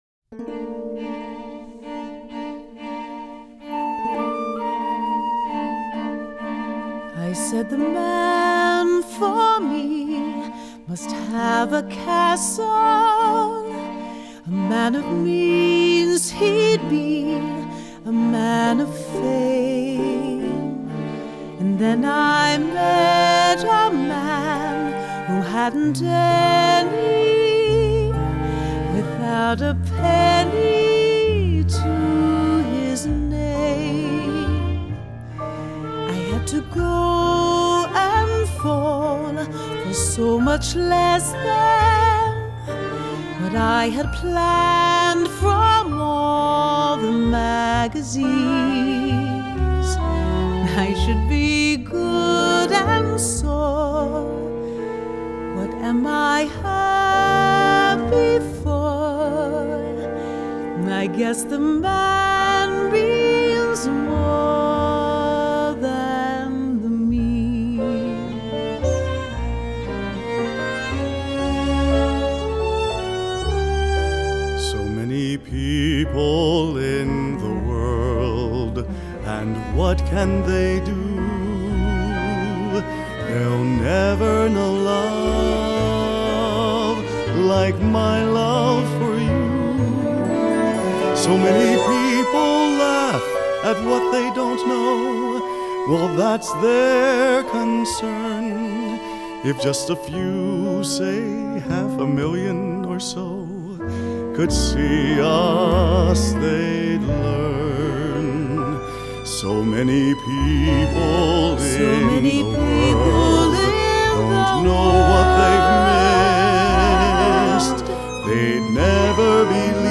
Original Broadway Cast Recording